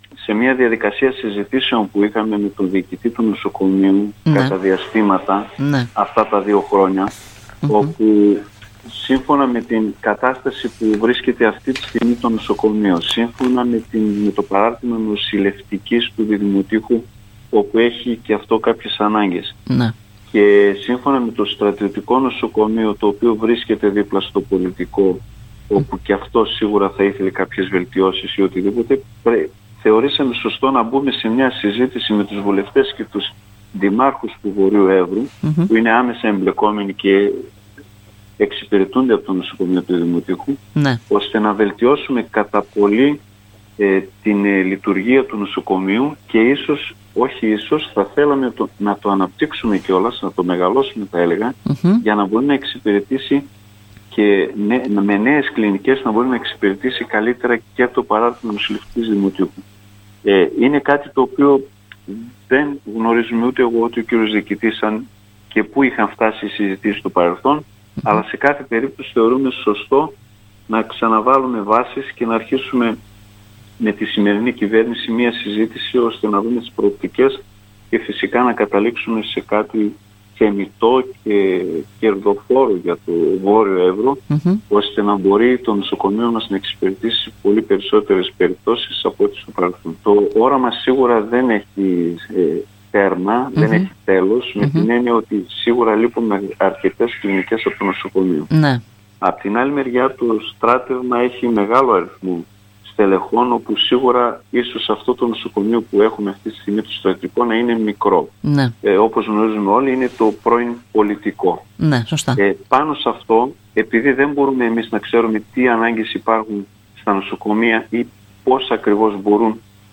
«Θέλουμε να ξαναβάλουμε νέες βάσεις και προοπτικές ώστε με την σημερινή κυβέρνηση να καταλήξουμε σε κάτι θεμιτό και κερδοφόρο για το β Έβρο» τόνισε μιλώντας στην ΕΡΤ Ορεστιάδας ο δήμαρχος Ρωμύλος Χατζηγιάννογλου.
Χατζηγιάννογλου-Ρωμύλος-Δήμαρχος-Διδυμοτείχου.mp3